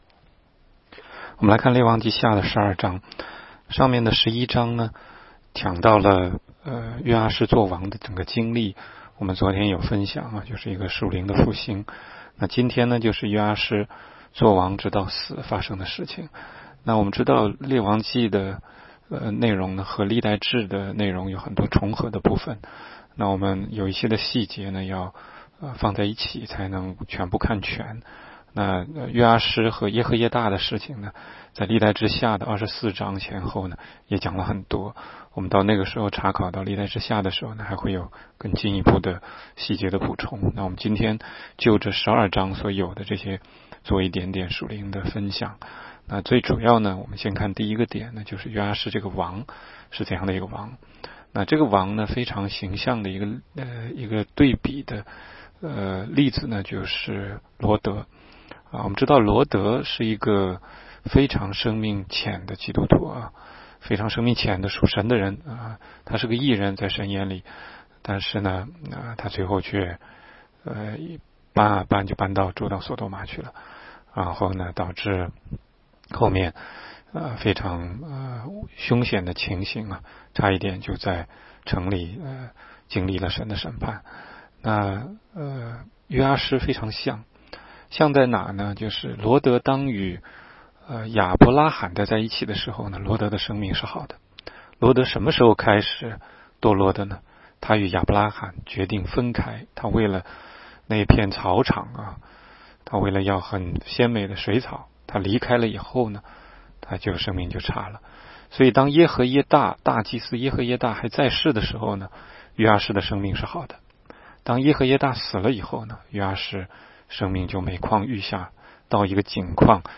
16街讲道录音 - 每日读经-《列王纪下》12章